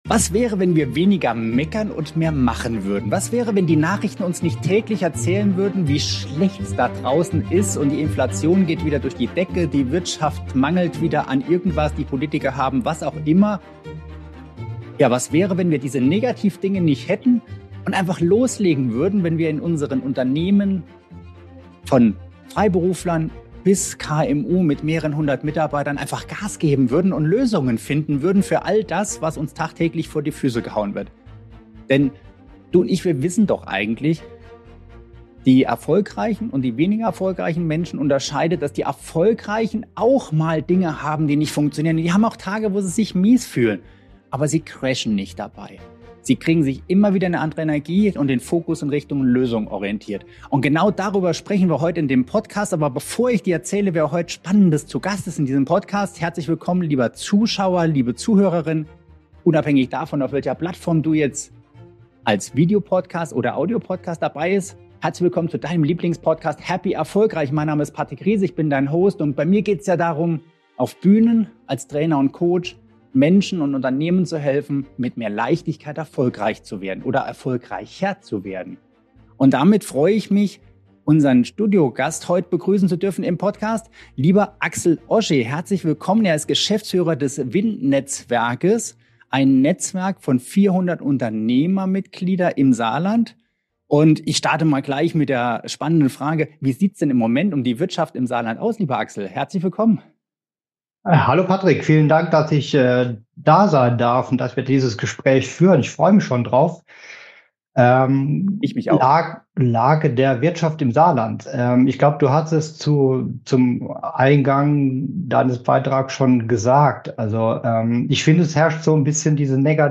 – ein Gespräch, das motiviert, anpackt und neue Perspektiven eröffnet.